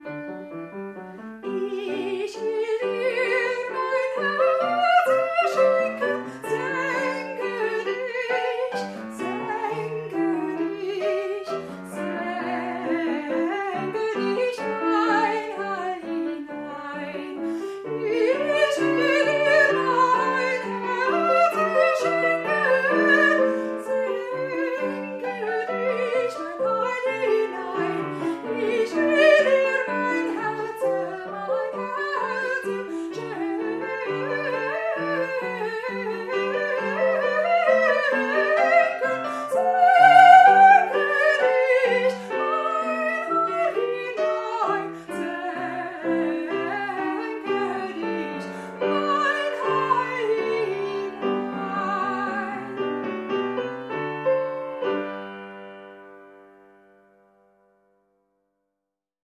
deze aria afkomstig?